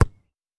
Ball Kick Normal.wav